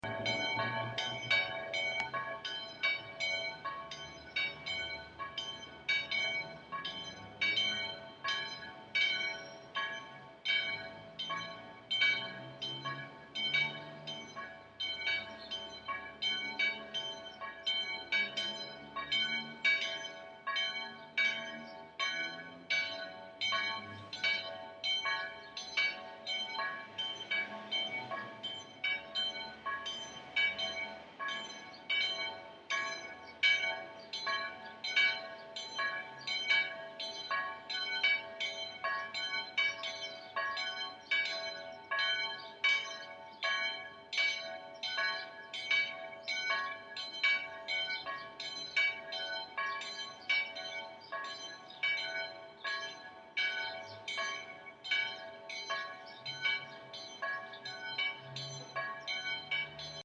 Seit es wieder in Betrieb ist, läutet es aber jeden Tag.
claragloeckli.mp3.mp3